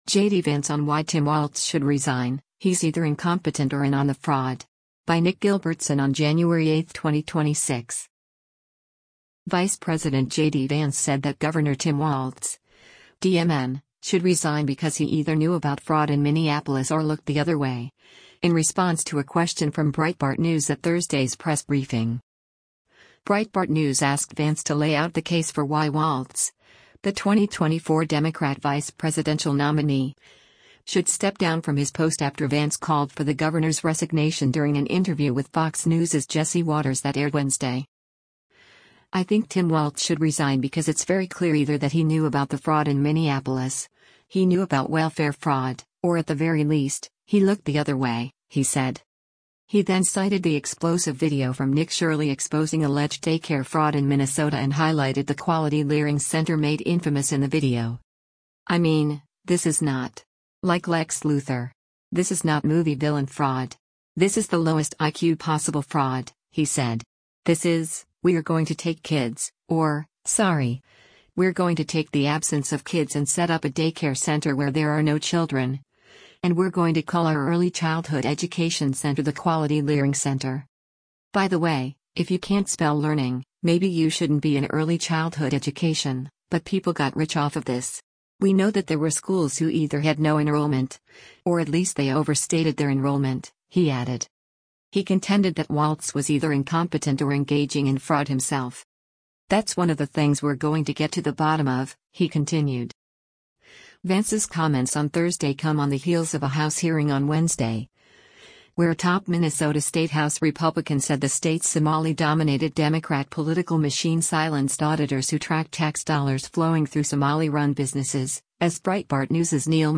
Vice President JD Vance said that Gov. Tim Walz (D-MN) should resign because he either knew about fraud in Minneapolis or “looked the other way,” in response to a question from Breitbart News at Thursday’s press briefing.